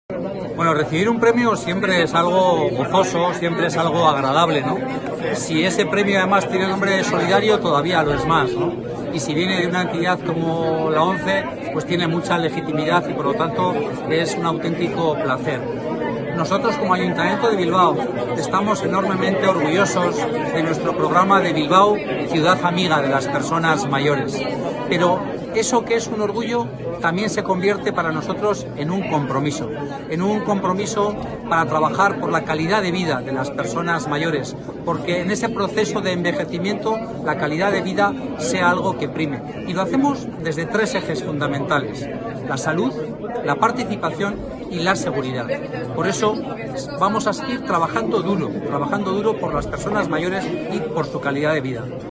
El auditorio Mitxelena, del Bizkaia Aretoa en Bilbao, sirvió de escenario para que la Organización agradeciera y homenajeara a toda la sociedad vasca por la solidaria actitud con la que día a día le apoya a través de la compra de sus productos de juego responsable.